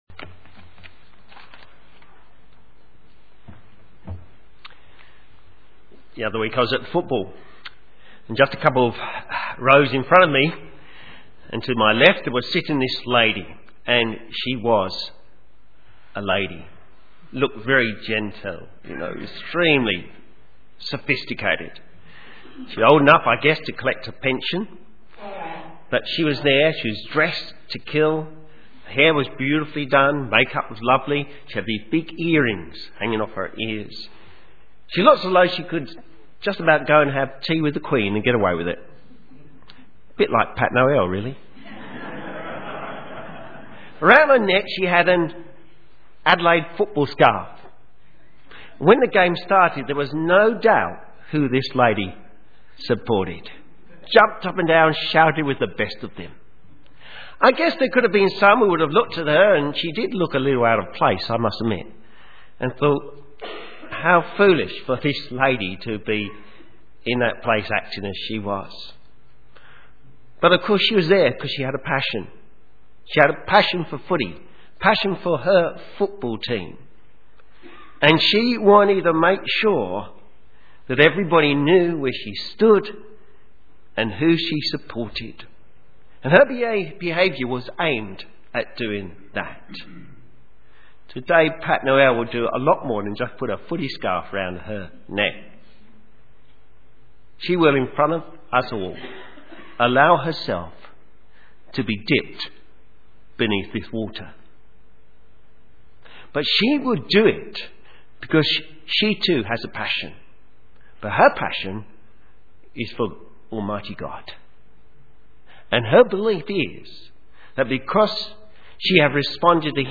Sermon
Baptism: Determined Love Luke 15 Synopsis A baptismal service for an older lady. Stories of the lost sheep, coin, and son from Luke 15 used to show God's determined love for lost souls, whether they are lost through misfortune or by wandering away(lost sheep).